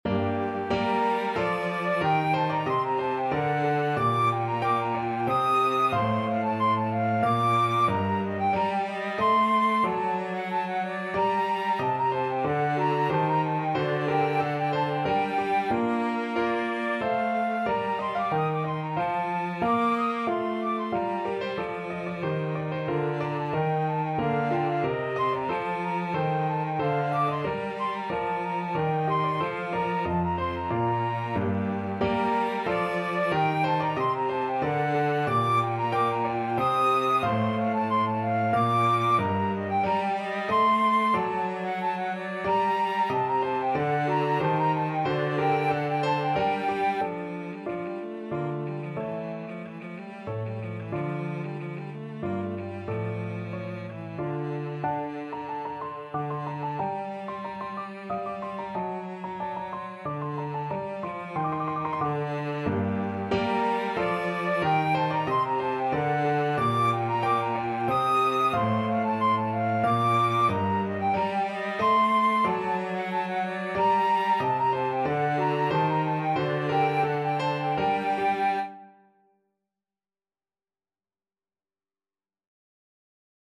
3/2 (View more 3/2 Music)
Allegro Moderato = c. 92 (View more music marked Allegro)
Classical (View more Classical Violin-Flute Duet Music)